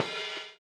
LO FI 10 OH.wav